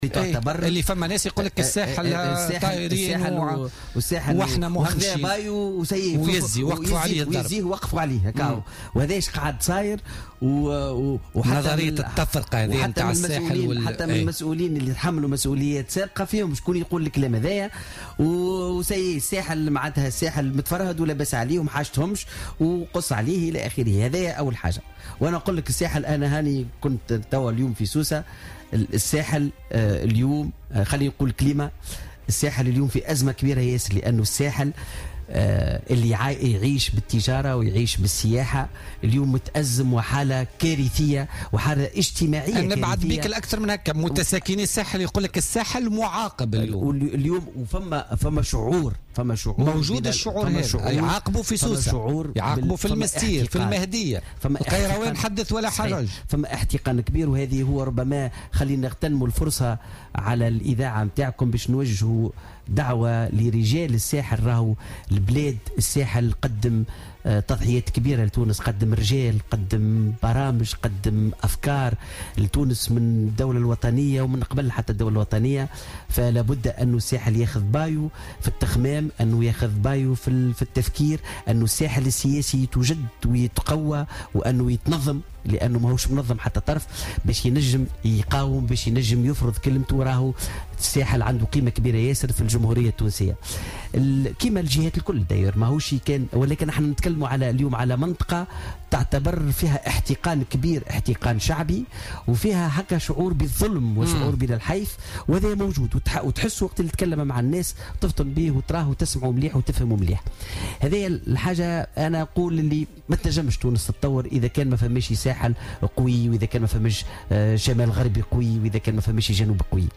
ووصف ضيف برنامج "بوليتيكا" اليوم الاثنين الوضع في الساحل بـ "الحالة الكارثية" بسبب ركود التجارة والسياحة، على إثر زيارة قام بها اليوم إلى سوسة.